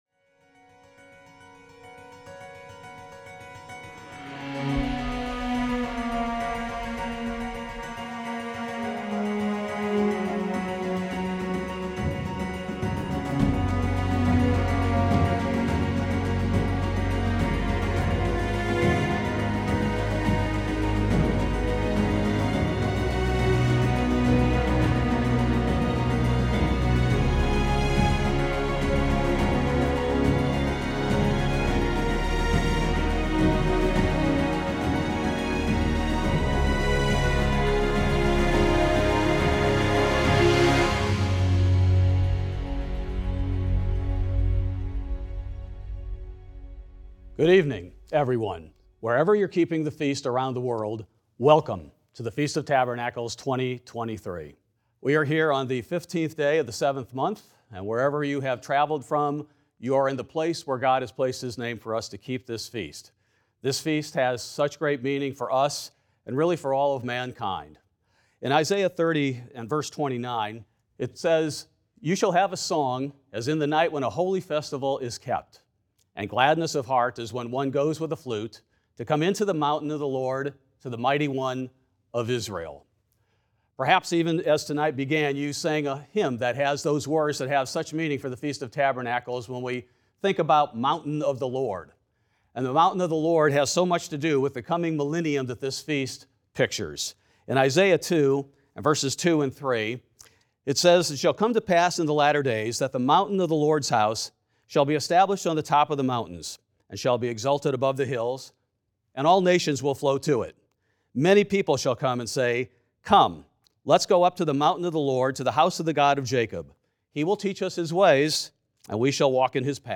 This sermon was given at the Panama City Beach, Florida 2023, Branson, Missouri 2023, Cincinnati, Ohio 2023, Estes Park, Colorado 2023, Galveston, Texas 2023, Gatlinburg, Tennessee 2023, Jekyll Island, Georgia 2023, Lake Geneva, Wisconsin 2023, Ocean City, Maryland 2023, Spokane Valley, Washington 2023 and St. George, Utah 2023 Feast sites.